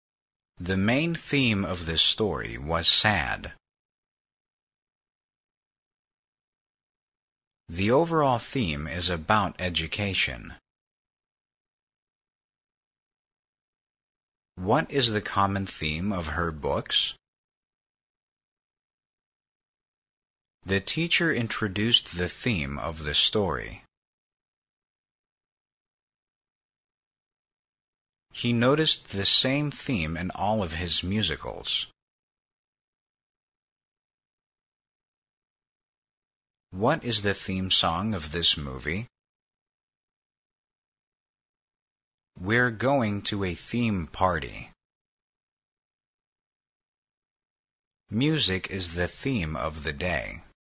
theme-pause.mp3